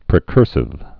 (prĭ-kûrsĭv)